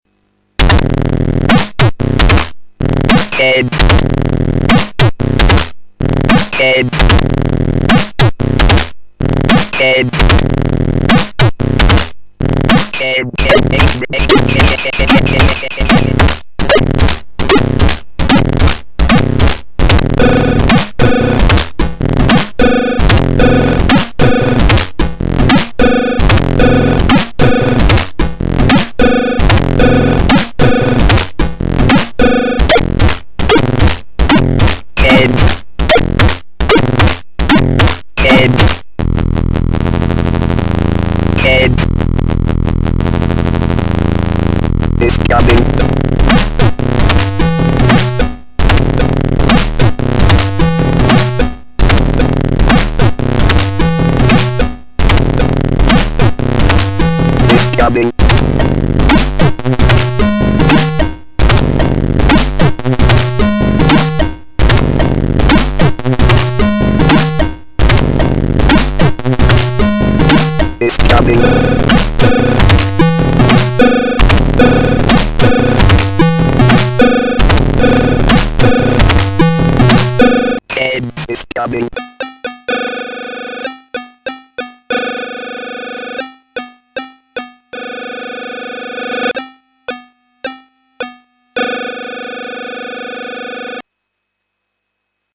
Most things in this archive have been created on vintage computers: the Acorn Electron, Acorn BBC Master 128, Commodore 64, ZX81 and Atari STe. Some pix have been drawn on a modern PC with a Wacom pen and the tunes are mostly made up of sounds sampled from the vintage machines then mixed on the PC.
The music on this site is all public domain and classified as BINARYWAVE. (cos it's made on puters)